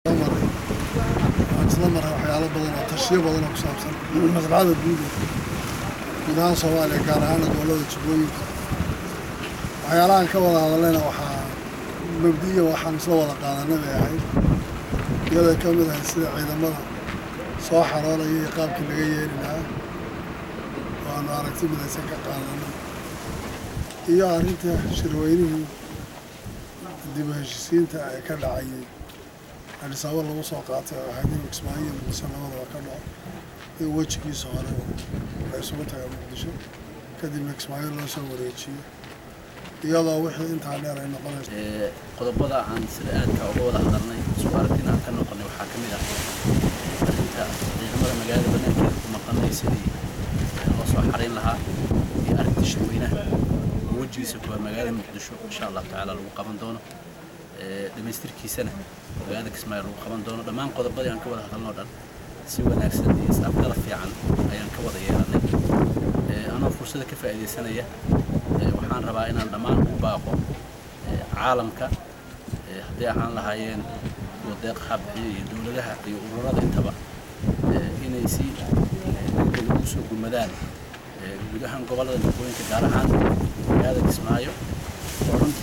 Kadib shir ay albaabadu u xirnaayeen oo muddo saacado ah uga socday xarunta Madaxtooyada Jubba State ee magaalada Kismaayo waftigii ka socday Dowladda Soomaaliya iyo masuuliyiinta maamulka Jubba ayaa labada dhinac shir jaraa’id oo ay galabta qabteen waxay ku shaaciyeen in la isku afgartay qodobadii laga wada hadlay.
Wasiirka arrimaha gudaha ee Xukuumadda Soomaaliya C/kariin Xuseen Guulleed iyo Hoggaamiyaha Jubba State Axamed Maxamed Islaam ayaa ka hadlay arrimahaasi.